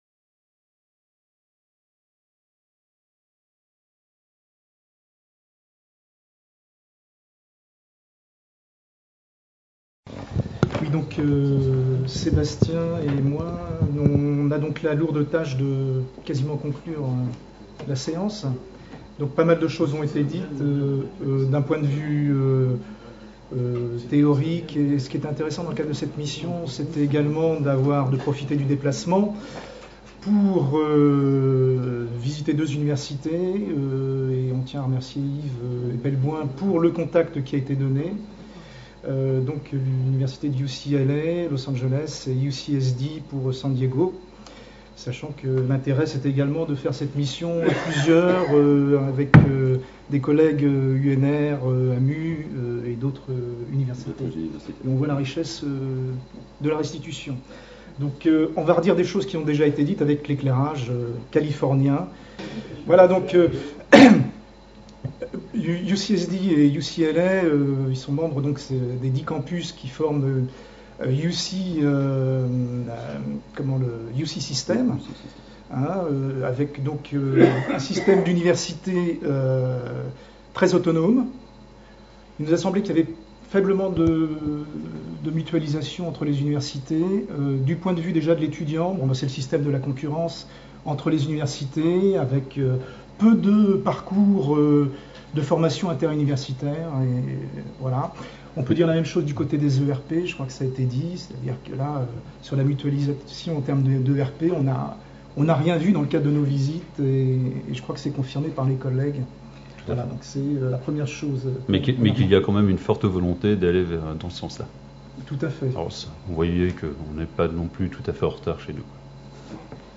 Restitution de la mission à EDUCAUSE organisée par l’AMUE le 8 novembre 2013 à la maison des universités. Les participants de la délégation française partagent les principaux sujets abordés à EDUCAUSE 2013 et les bonnes pratiques identifiées à l’occasion de pré-visites aux universités américaines.